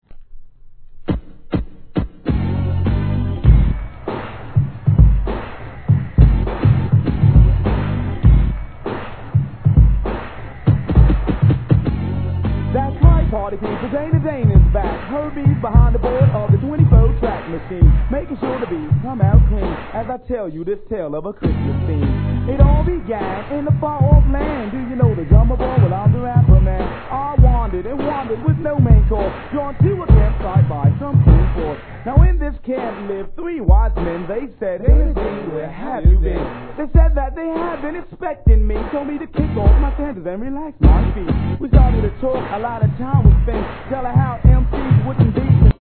HIP HOP/R&B
BIG BEAT使い、1987年OLD SCHOOL !!